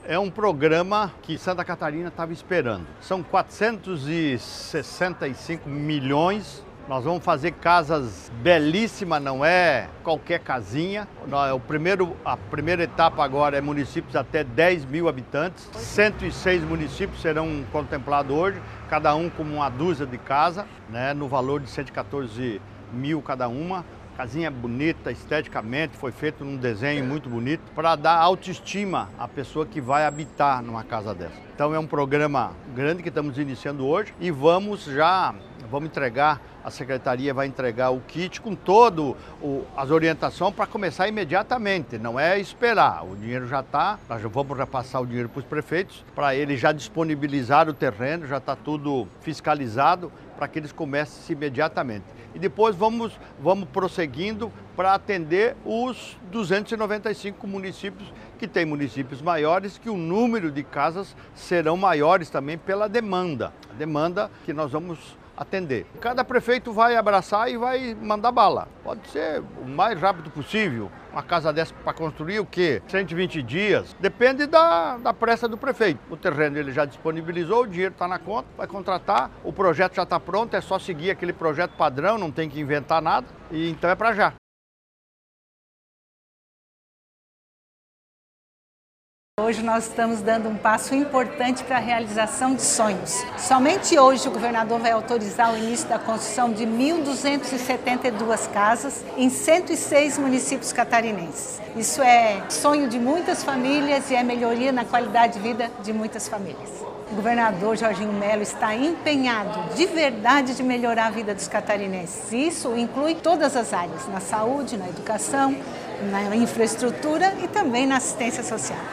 O governador Jorginho Mello anunciou, nesta terça, 27, os 106 municípios de até 10 mil habitantes beneficiados na primeira etapa do Programa Casa Catarina. O evento foi realizado no Teatro Álvaro de Carvalho (TAC), no Centro de Florianópolis.
Segundo o governador, a ideia é prosseguir com o programa e atender todos os 295 municípios do estado:
A secretária de Estado da Assistência Social, Mulher e Família, Adeliana Dal Pont, comenta que hoje será dado início a construção de mais de 1.200 casas por meio do programa: